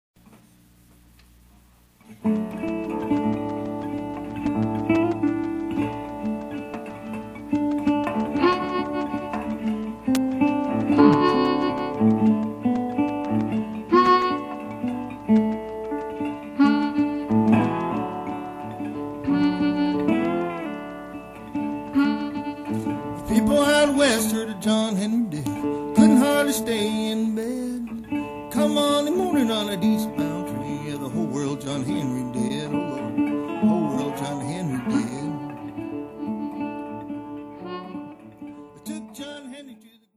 Smooth.